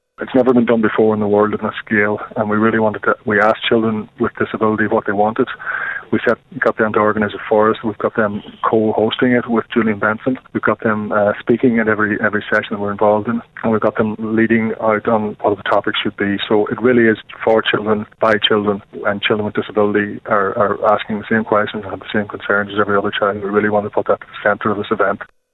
Ombudsman for Children, Dr Niall Muldoon, says they listened to what the young people wanted: